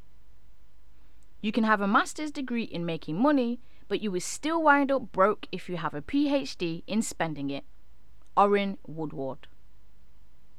I am using a 4 year old laptop with a cheap USB condenser mic!
I can hear the faint echo after “Broke”.
It sounds like an electronic echo, rather than an acoustic echo from the room.